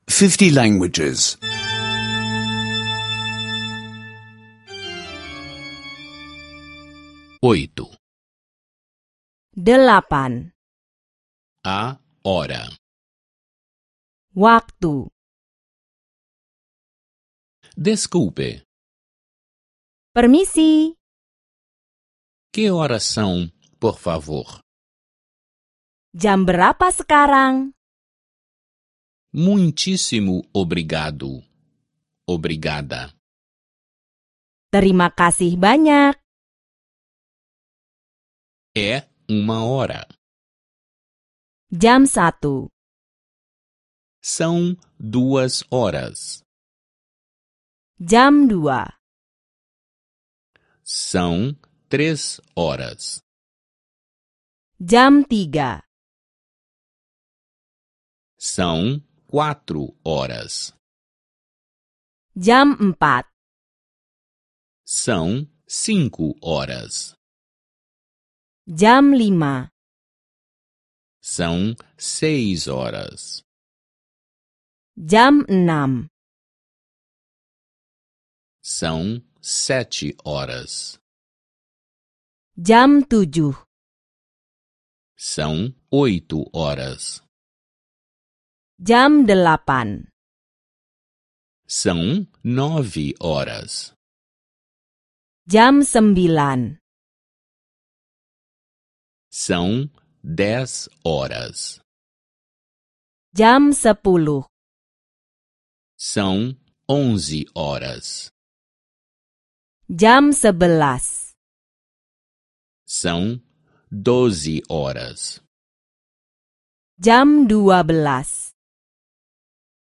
Aulas de indonésio em áudio — escute online